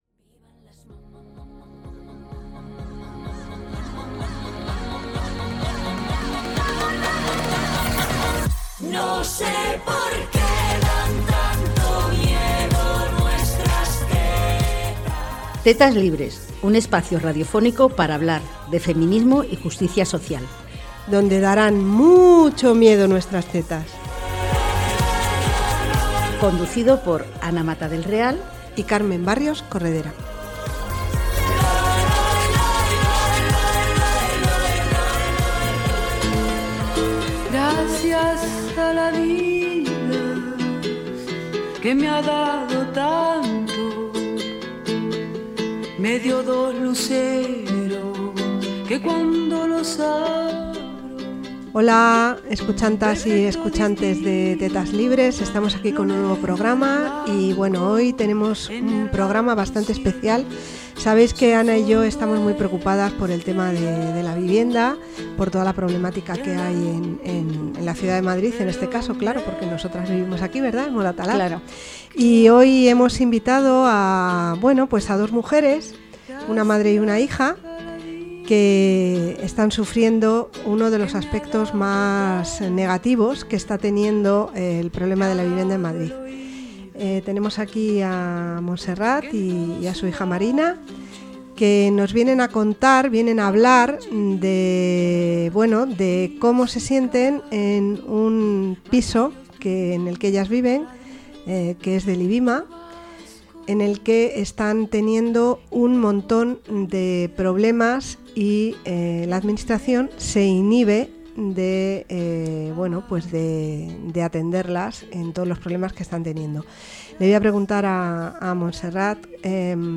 entrevistan